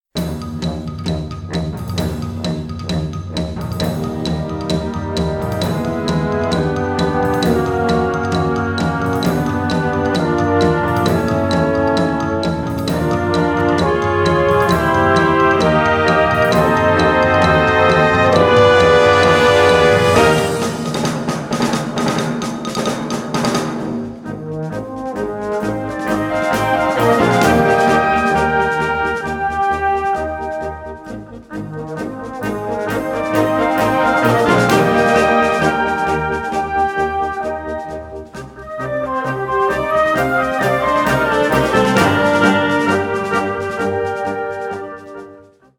Categorie Harmonie/Fanfare/Brass-orkest
Subcategorie Concertmuziek
Bezetting Ha (harmonieorkest)
Een meeslepend en uitdagend concert- of wedstrijdwerk!